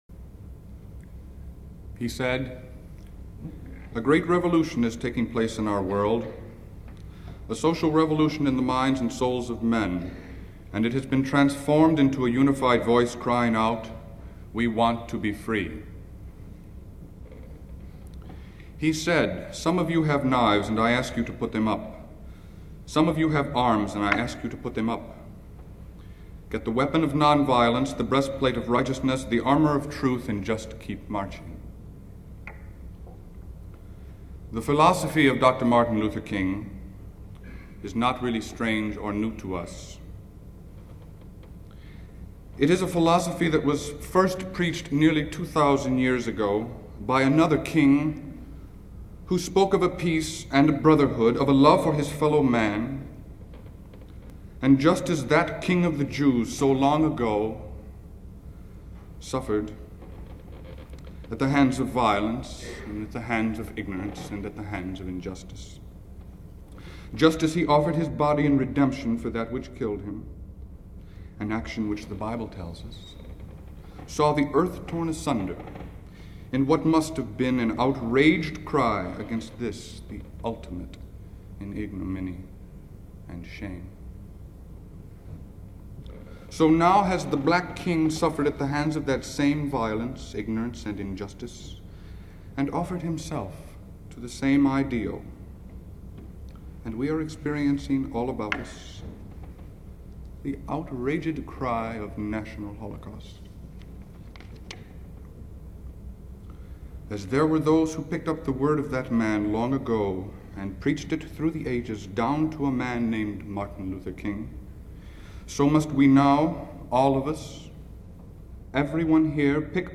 LISTEN: Convocation in honor of Martin Luther King Jr.